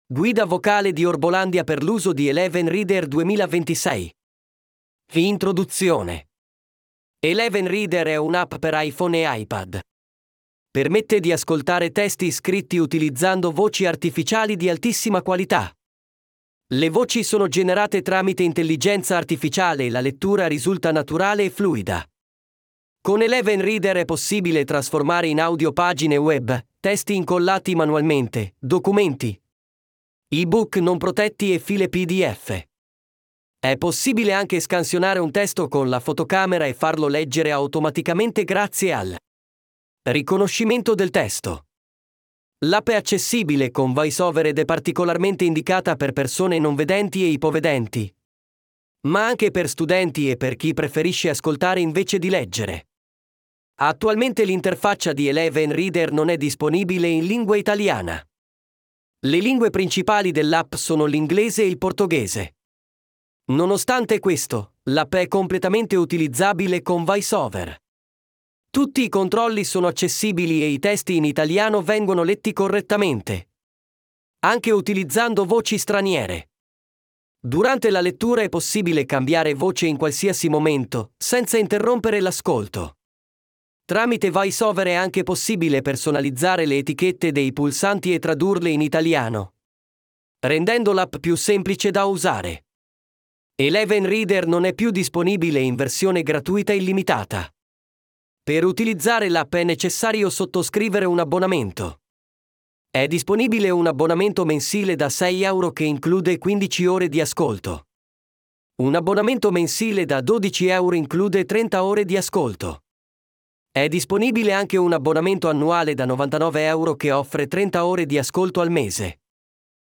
Eleven Reader è un’app per iPhone e iPad che trasforma testi, documenti, PDF, pagine web e scansioni in audio di altissima qualità grazie all’intelligenza artificiale.